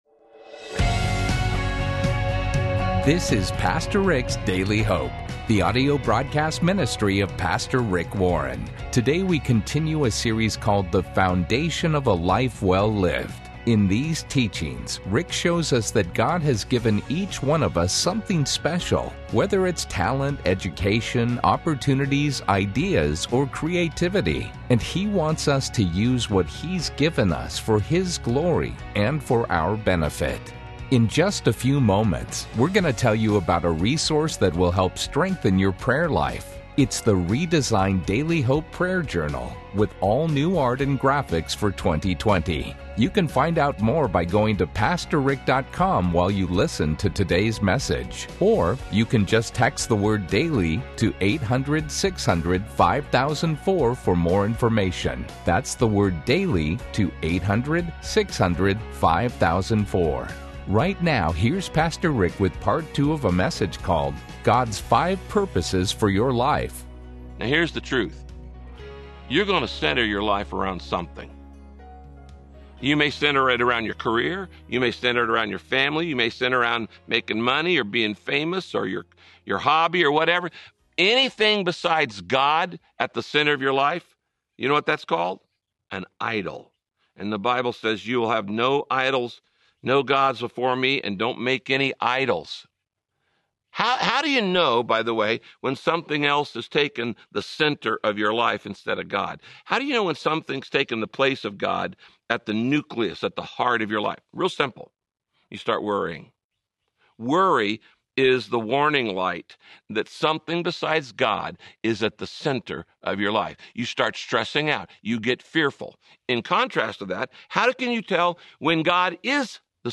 Radio Broadcast